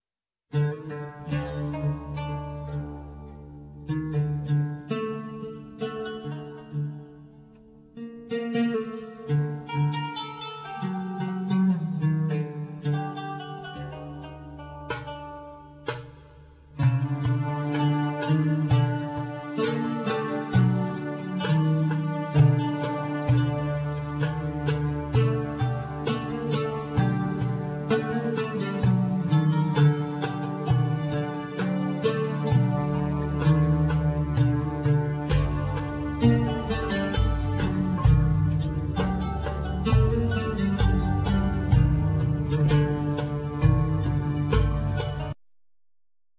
Ud, Bendir, Nay, Turkish Qanun, Tajira, Darbuka,Keyboard